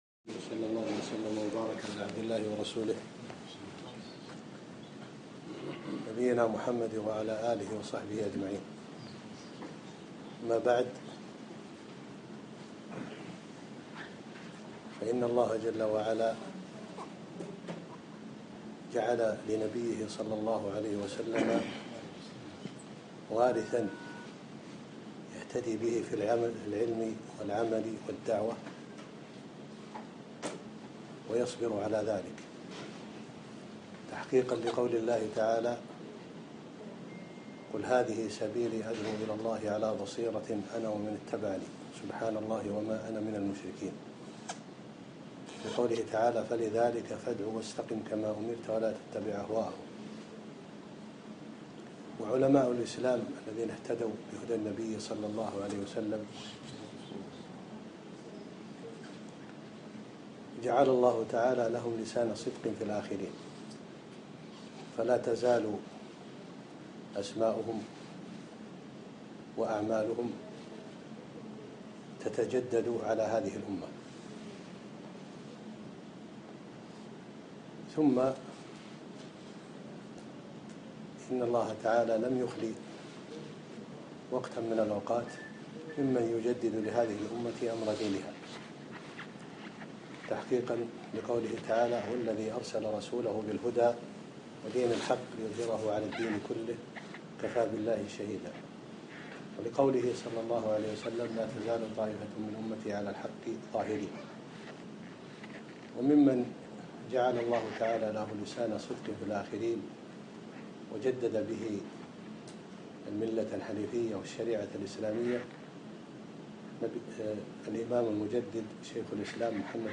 أقيمت الكلمة